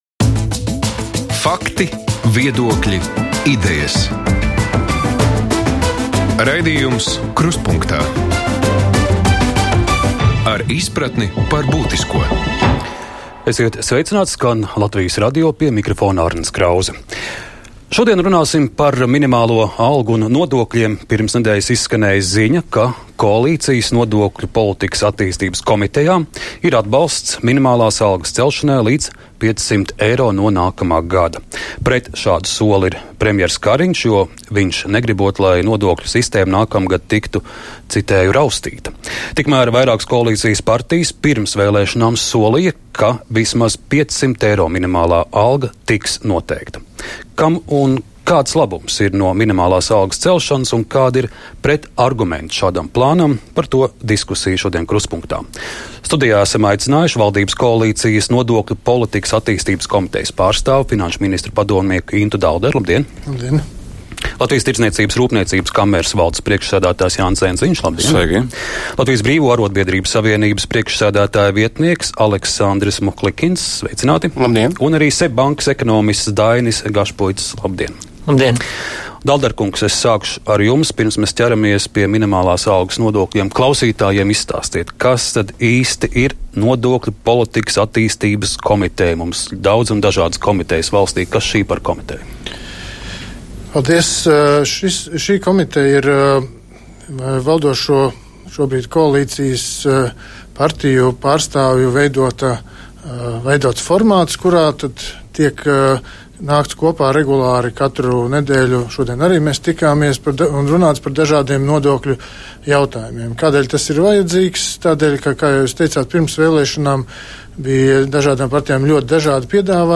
Diskusija par minimālās algas celšanu.